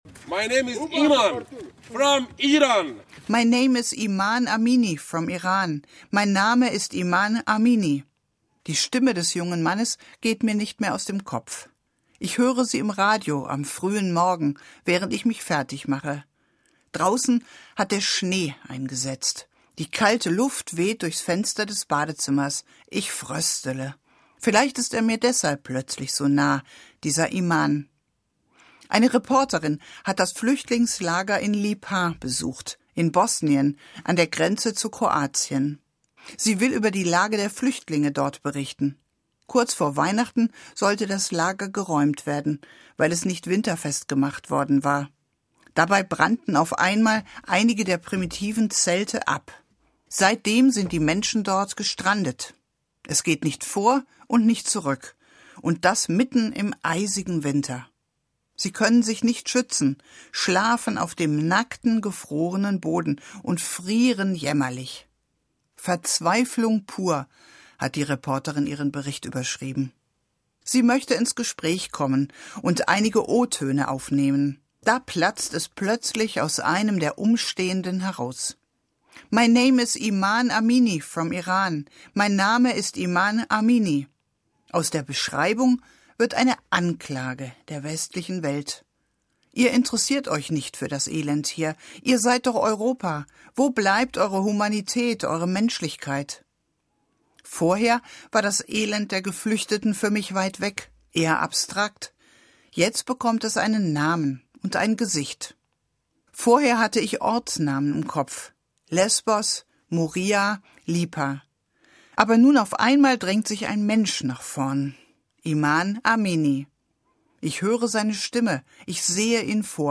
Musik Olivier Messiaen, Vingt Regards sur l’Enfant-Jésus, No. I